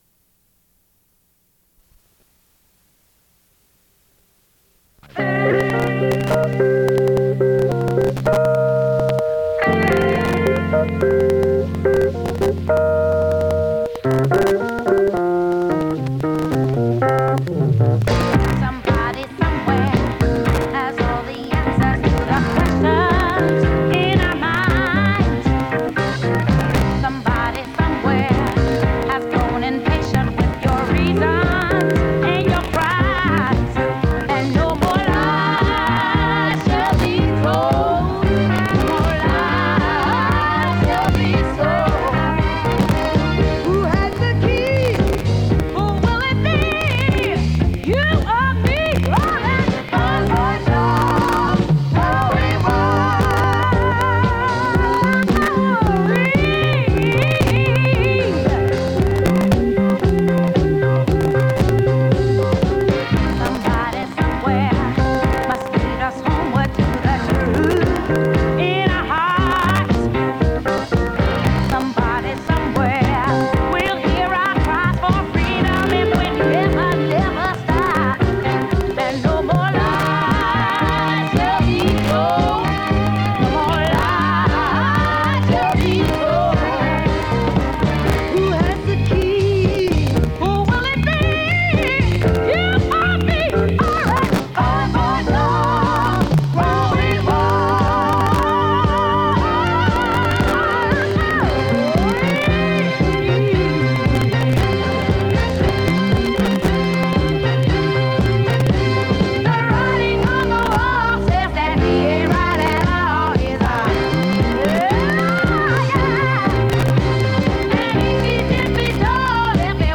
Margaret Walker Alexander (poet) discusses the problems facing the Black woman writer. Mari Evans (poet) discusses her experience as a Black woman writer.
Radio talk shows